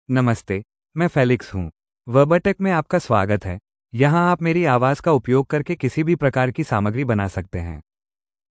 FelixMale Hindi AI voice
Felix is a male AI voice for Hindi (India).
Voice sample
Listen to Felix's male Hindi voice.
Felix delivers clear pronunciation with authentic India Hindi intonation, making your content sound professionally produced.